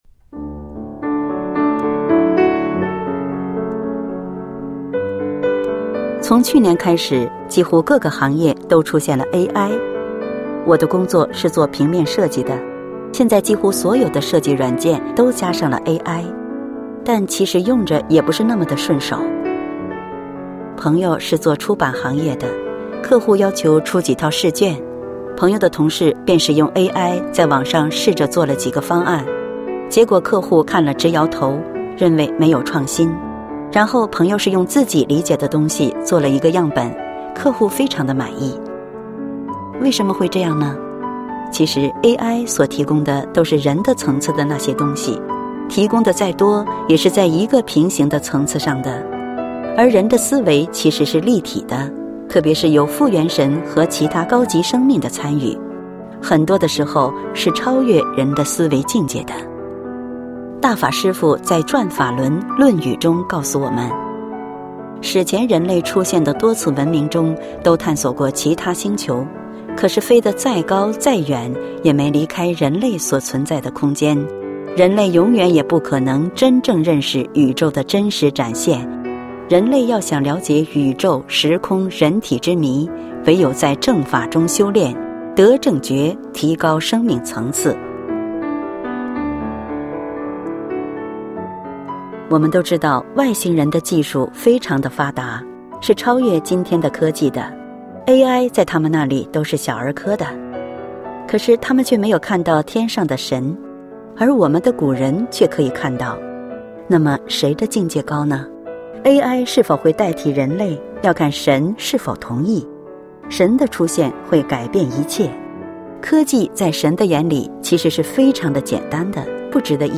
配樂散文朗誦（音頻）：AI能否超越人類（MP3）